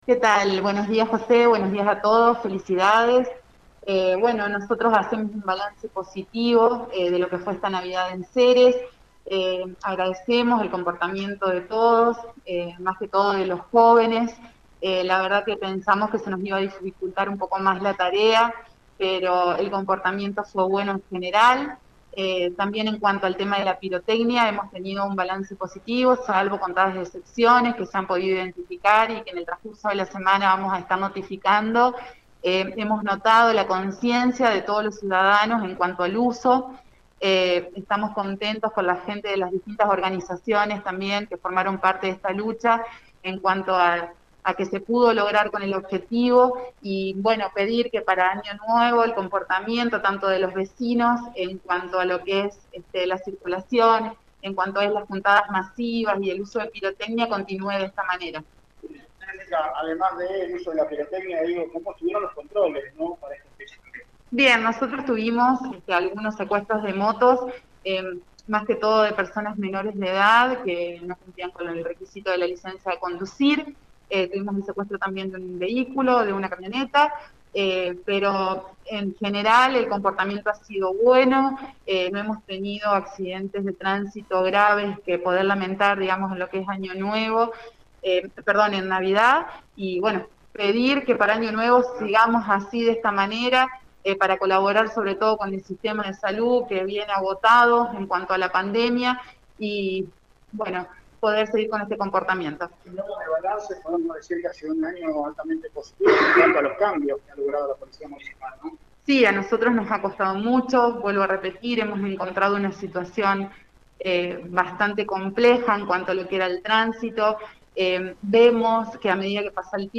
Hablamos con la Jueza de Faltas, Jesica Beder